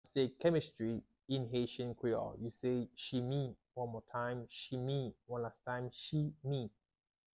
How to say "Chemistry" in Haitian Creole - "Chimi" pronunciation by a native Haitian tutor
“Chimi” Pronunciation in Haitian Creole by a native Haitian can be heard in the audio here or in the video below:
How-to-say-Chemistry-in-Haitian-Creole-Chimi-pronunciation-by-a-native-Haitian-tutor.mp3